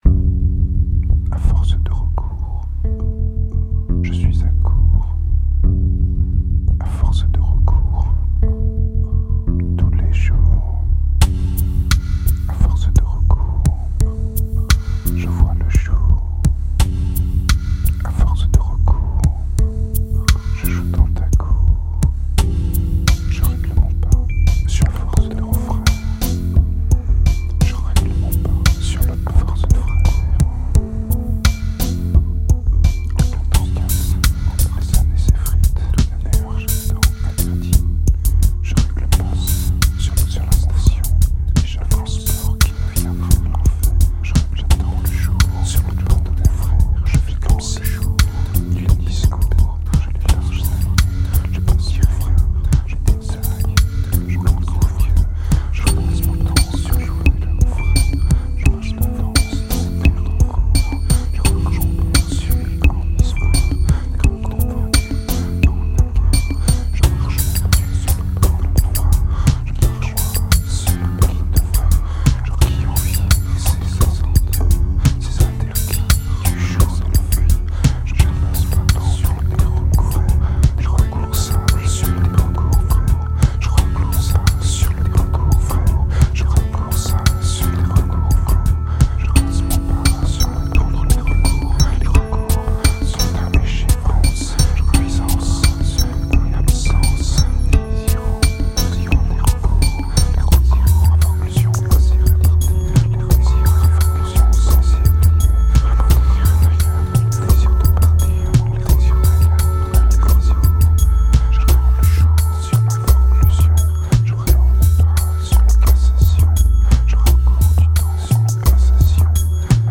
Tight and progressive, it seems to flow out more naturally.
86 BPM